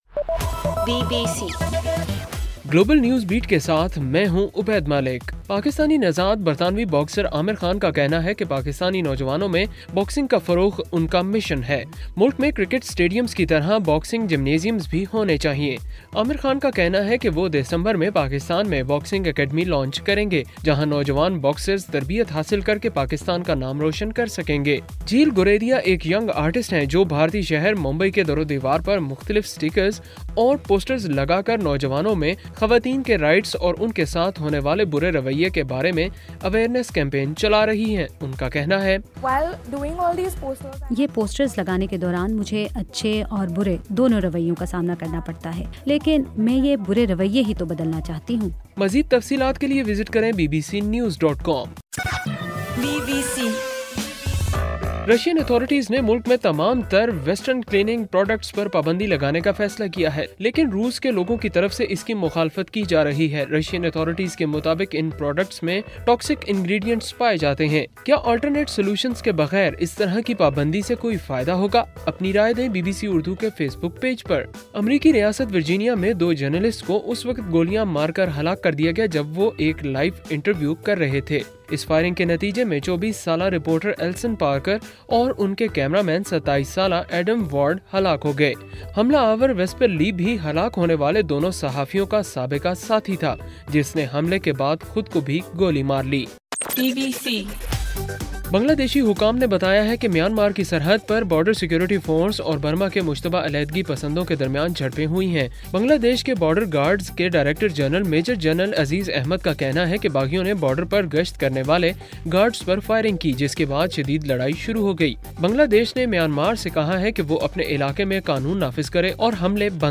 اگست 27: صبح 1 بجے کا گلوبل نیوز بیٹ بُلیٹن